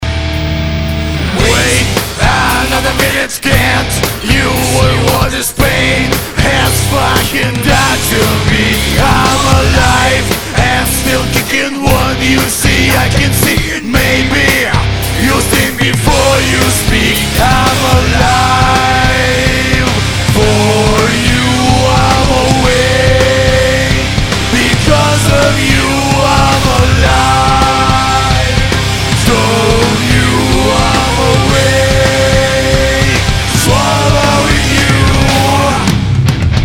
Вокал. Демо